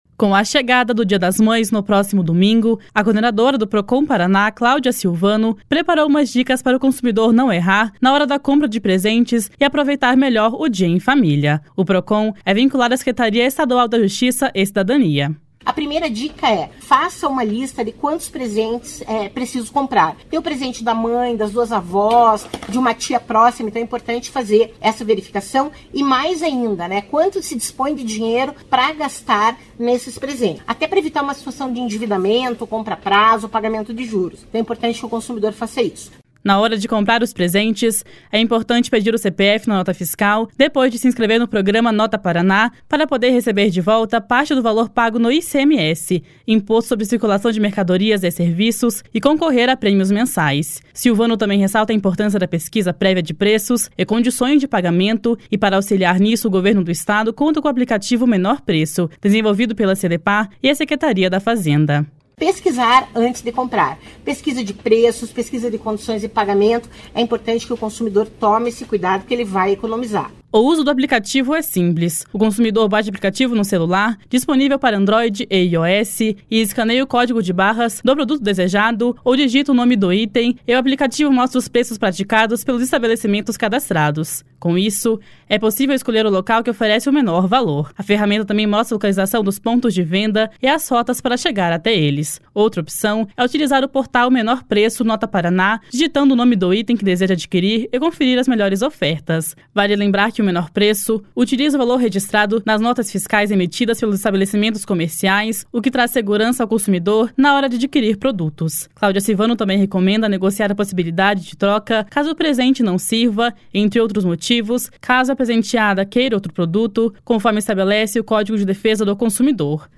// SONORA CLAUDIA SILVANO //